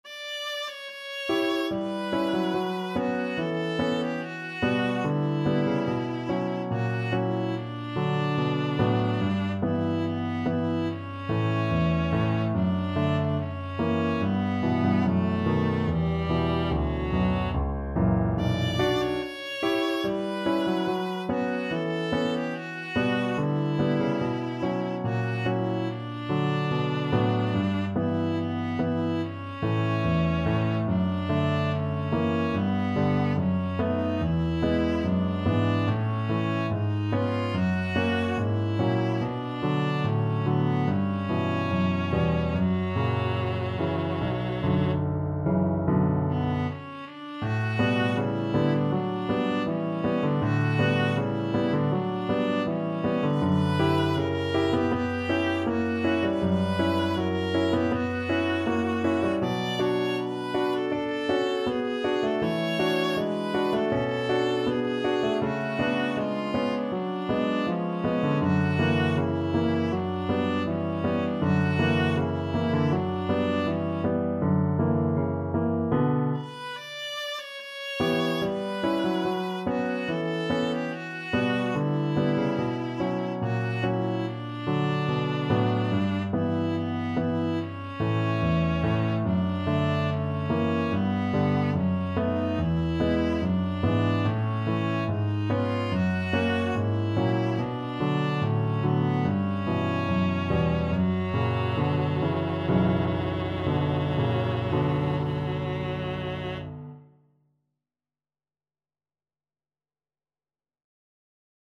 2/4 (View more 2/4 Music)
~ = 72 Andantino (View more music marked Andantino)
Classical (View more Classical Viola Music)